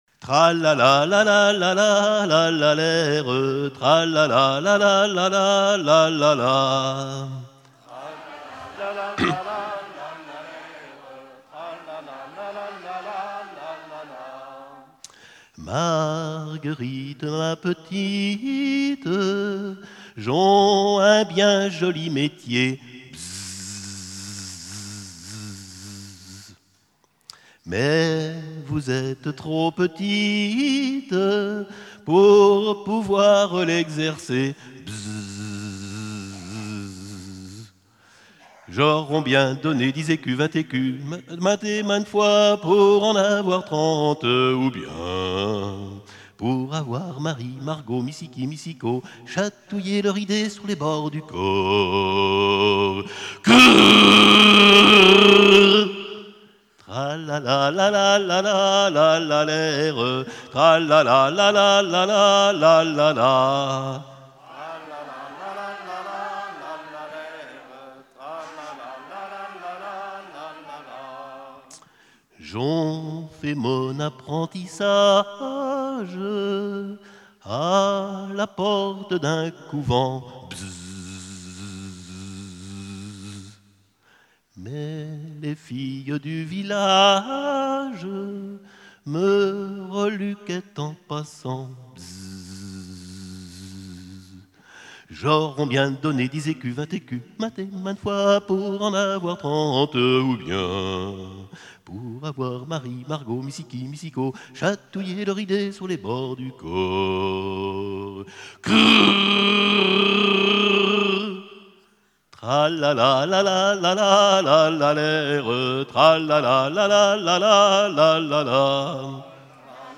Genre laisse
Festival de la chanson traditionnelle - chanteurs des cantons de Vendée
Pièce musicale inédite